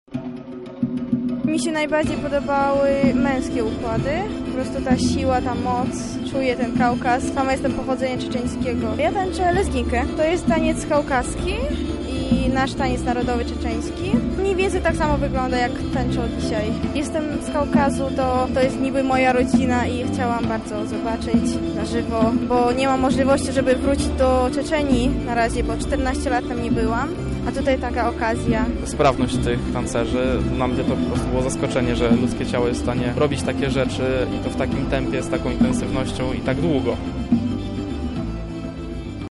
500 tancerzy z własną orkiestrą zaprezentowało się wczoraj przed lubelską publicznością. Artyści urozmaicili swój taniec narodowy współczesnymi krokami, elementami sztuk walki i akrobatyką.